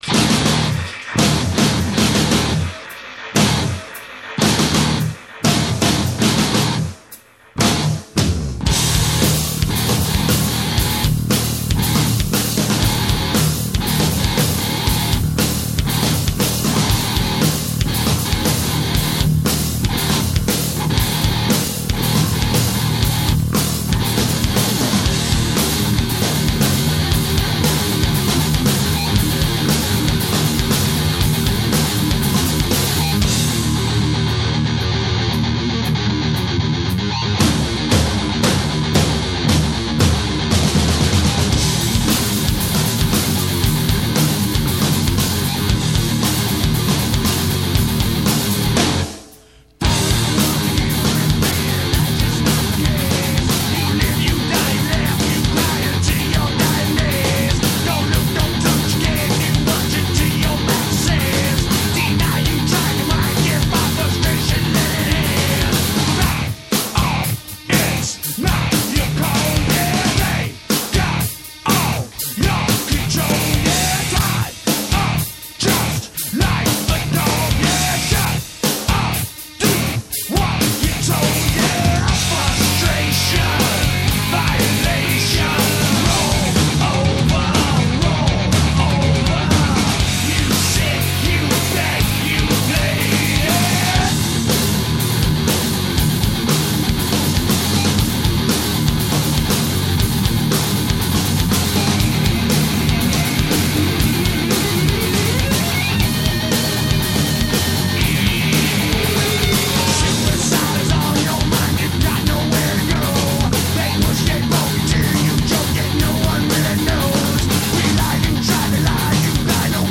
2.5 megabytes - mono